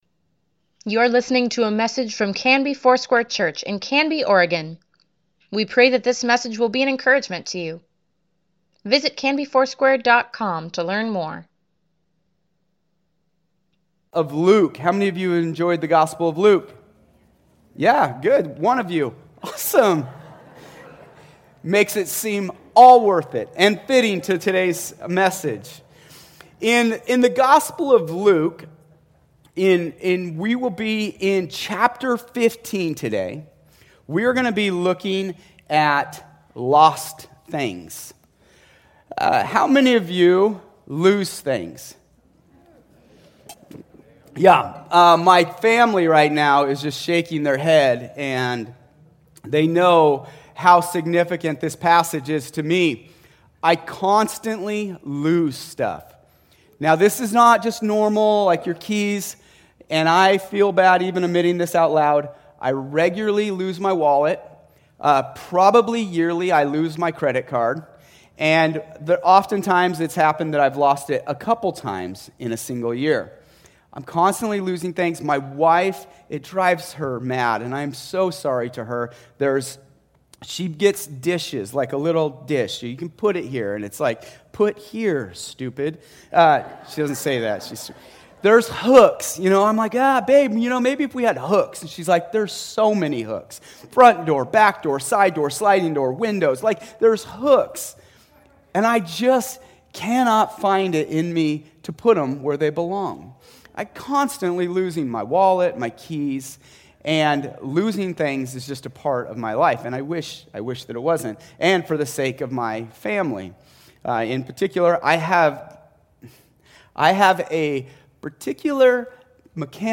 Weekly Email Water Baptism Prayer Events Sermons Give Care for Carus "Lost" - Jesus for Everyone, pt.17 May 16, 2021